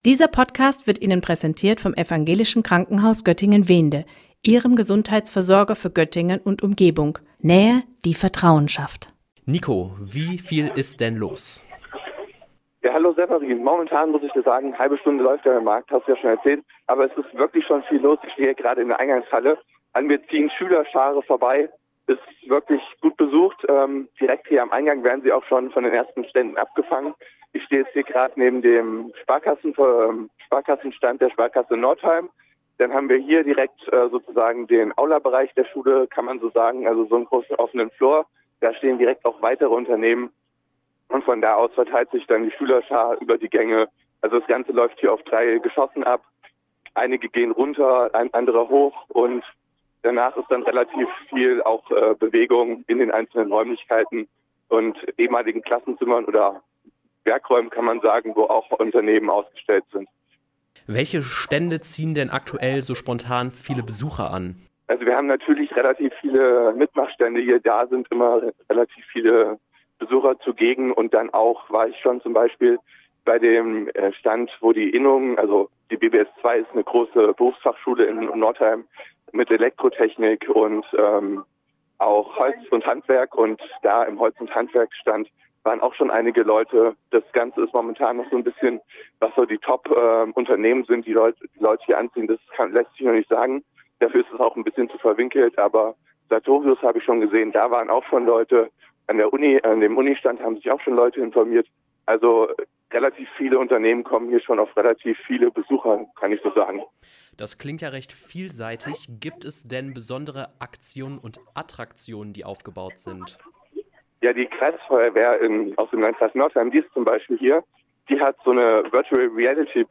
BerufsInfoMarkt in Northeim: Live-Schaltung aus der BBS2 Zur Messe